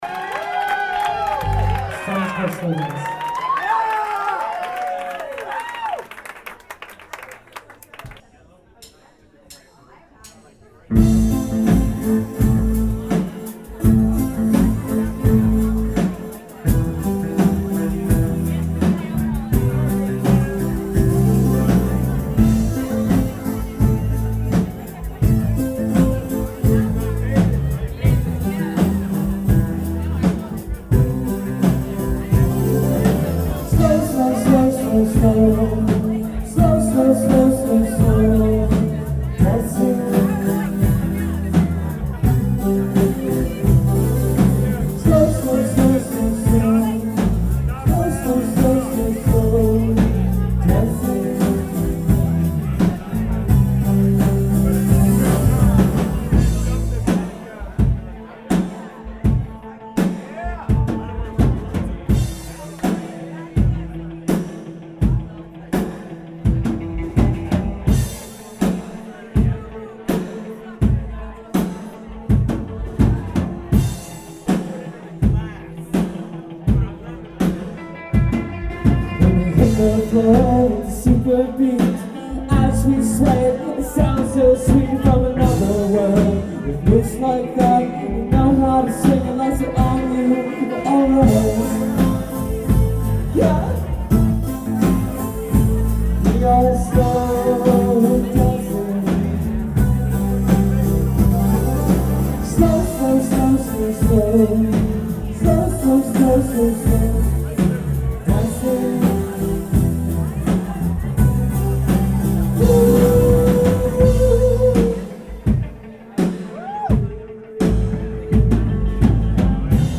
killer off-kilter phrasing
slow-burn international high-plains drifter charm